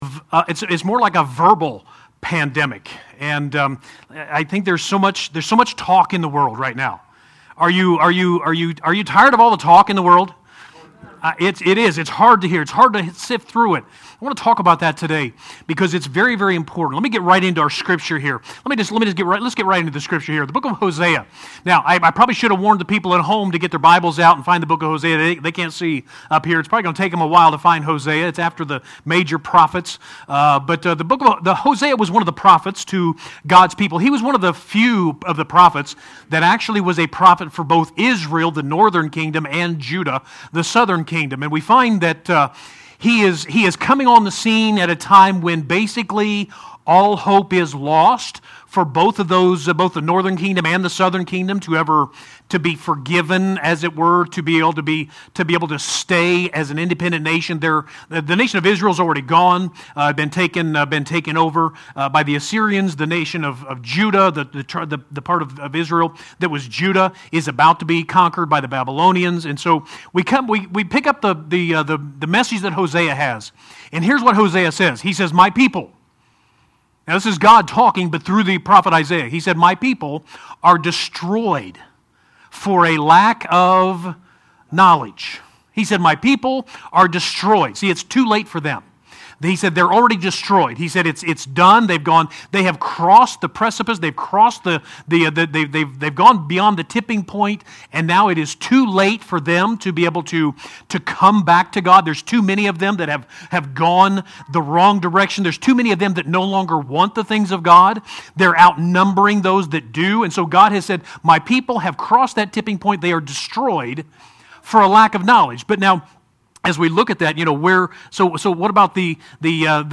7-12-20 Sunday Message – God’s Word and My Opinion